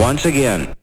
VOX SHORTS-1 0022.wav